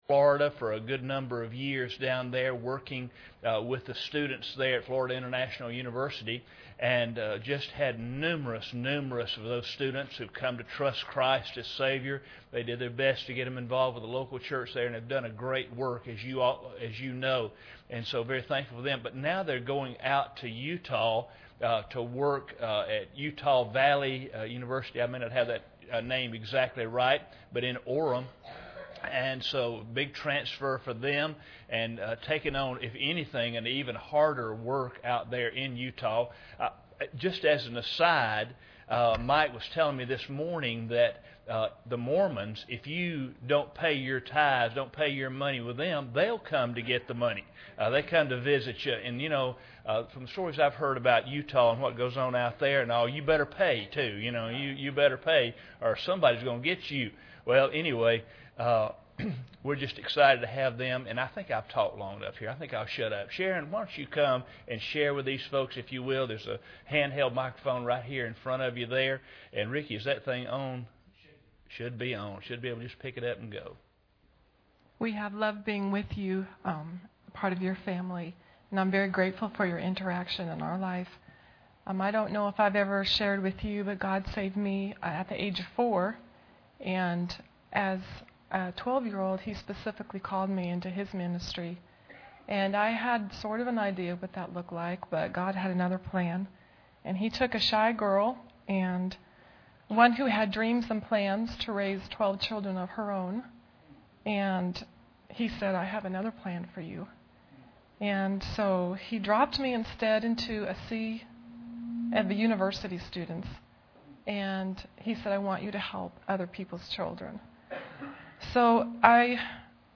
Series: 2014 Missions Conference Service Type: Sunday Evening